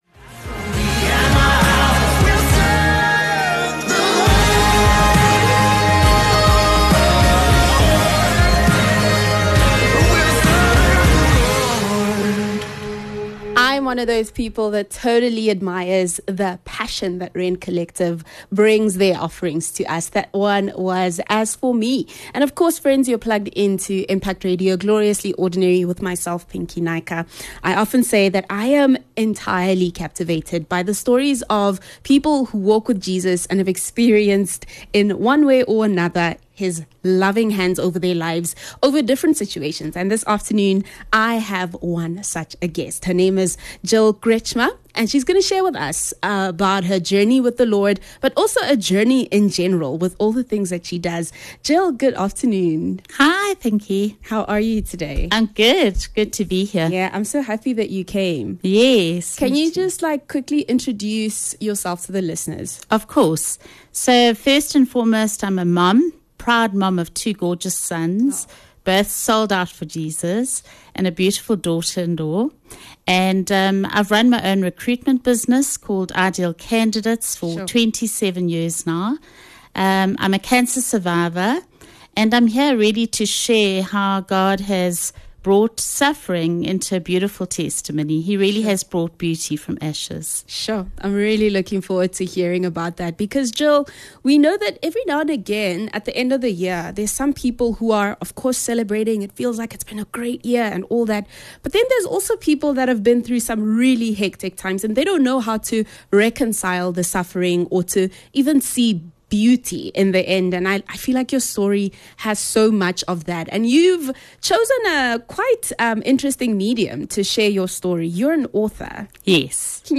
Anchor for Cancer Interview